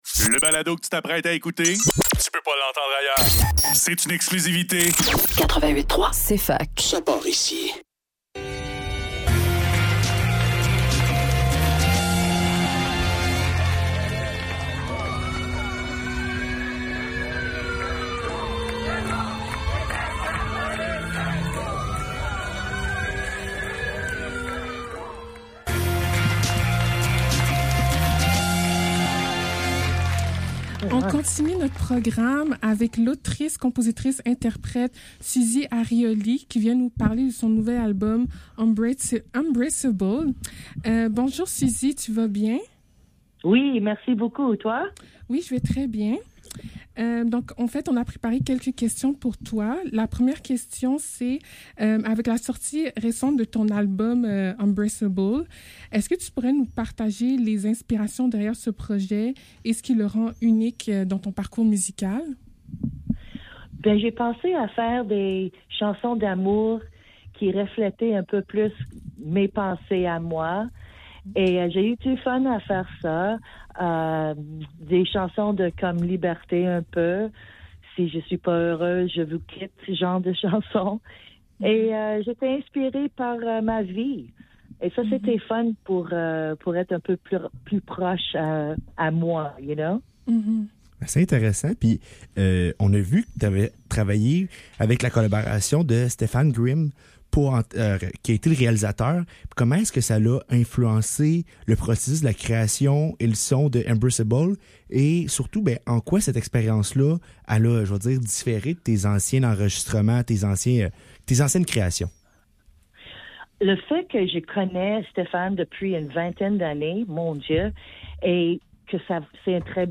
Faudrait que tout l'monde en parle - Entrevue avec Susie Arioli - 14 mars 2024